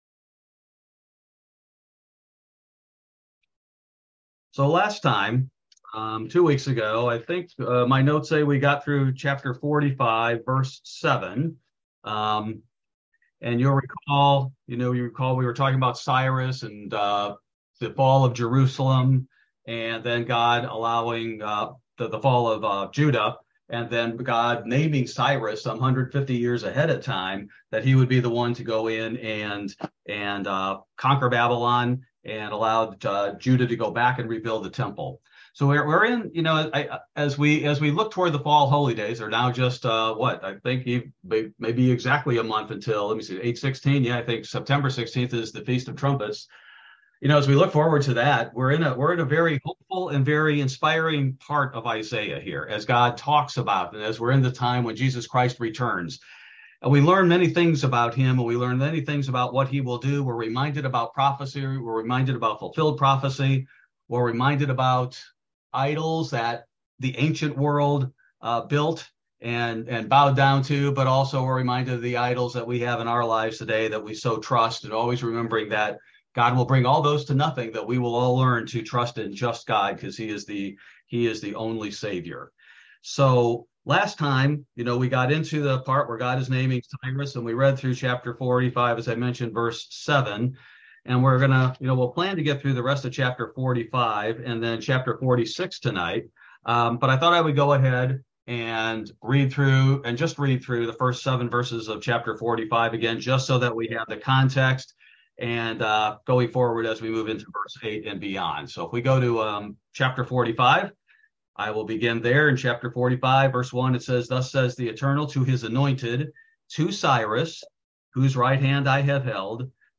This verse by verse Bible Study primarily covers Isaiah 45-46: God Did Not Create the Earth in "Tohu"